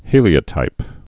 (hēlē-ə-tīp)